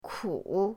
ku3.mp3